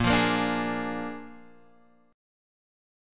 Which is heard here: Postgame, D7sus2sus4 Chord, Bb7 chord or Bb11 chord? Bb7 chord